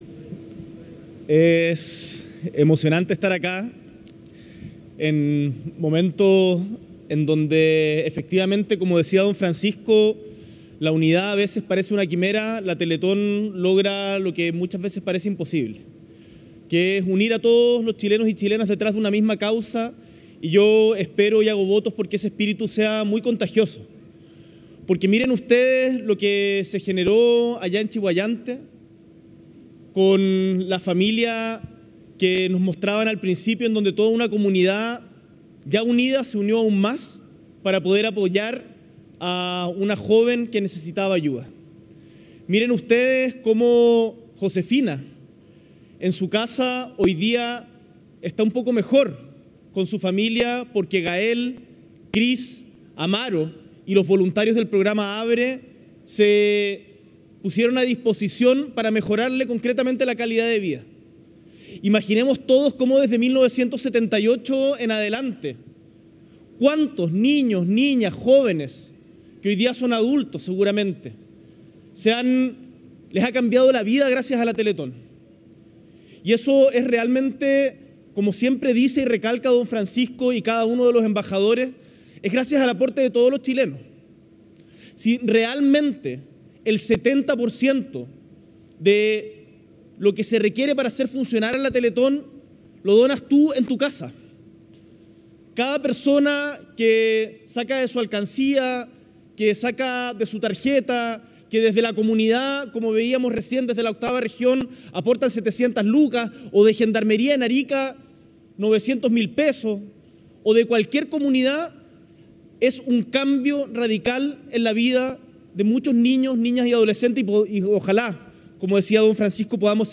S.E. el Presidente de la República, Gabriel Boric Font, asiste a la ceremonia de obertura de la Teletón 2024